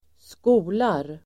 Uttal: [²sk'o:lar]